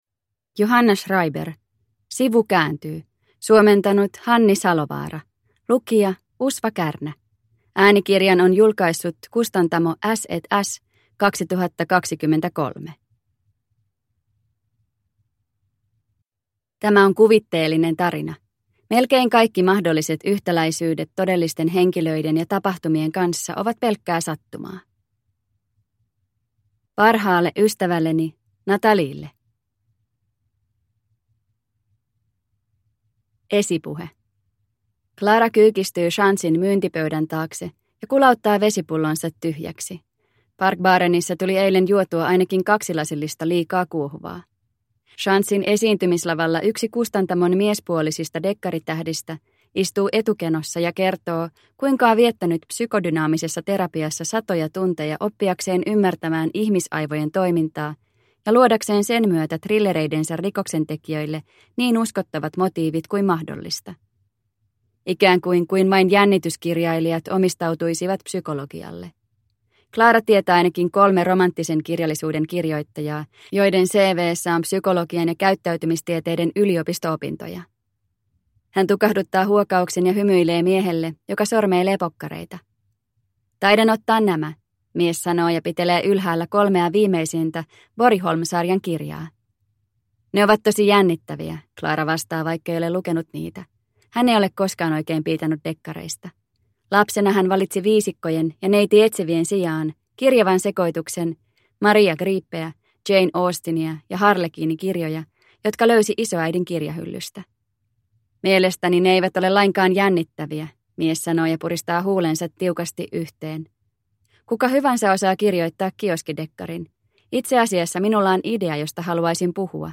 Sivu kääntyy – Ljudbok – Laddas ner